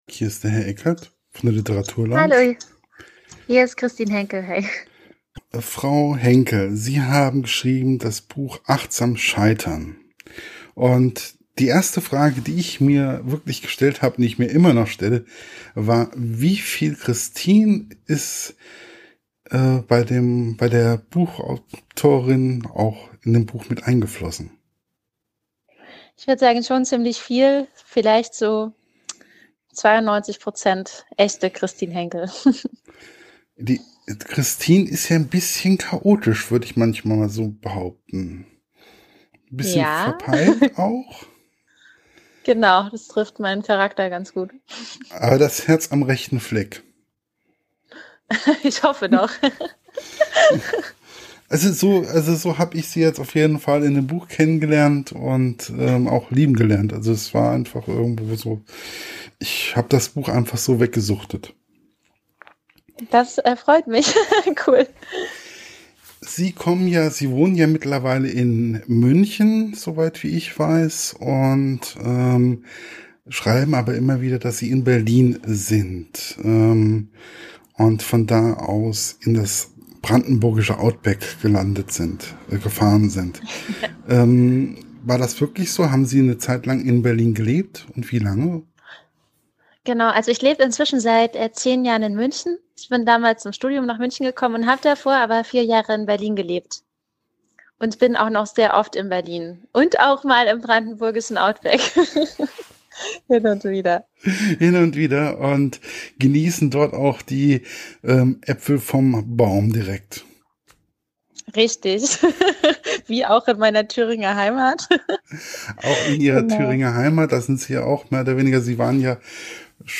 [Podcast-Interview]